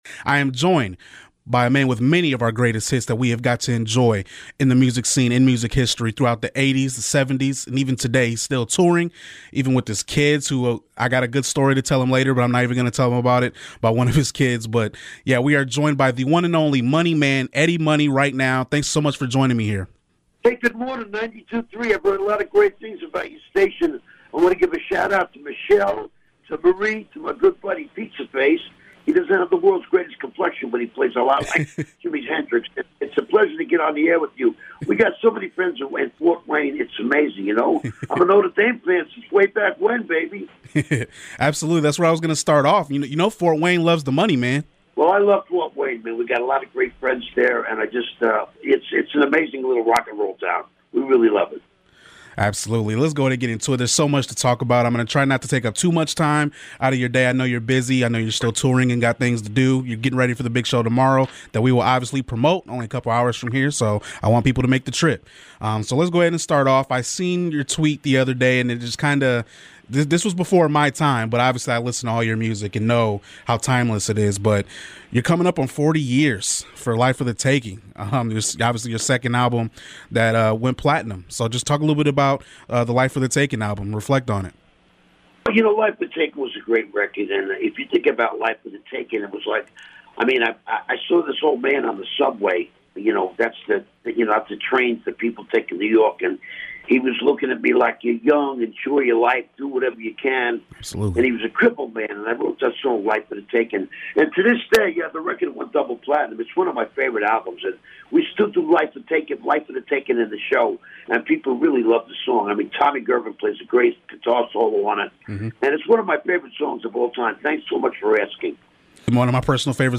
Interview with Eddie Money